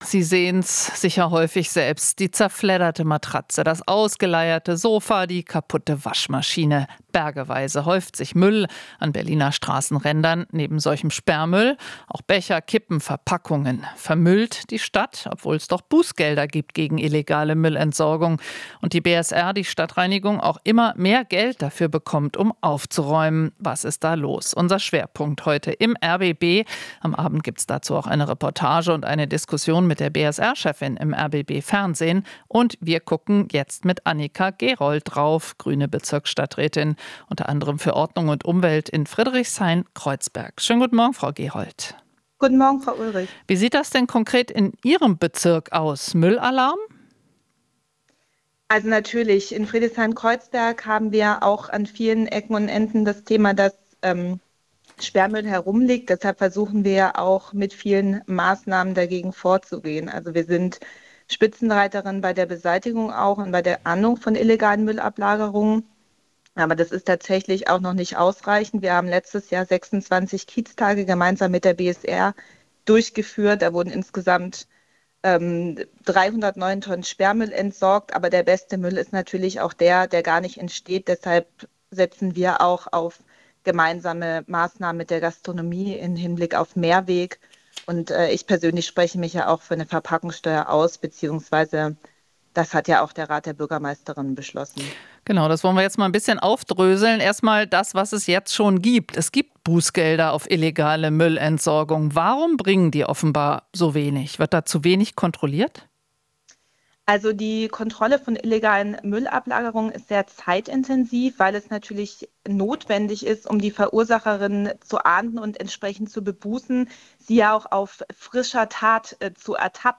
Alles was wichtig ist in der Hauptstadtregion - in Interviews, Berichten und Reportagen.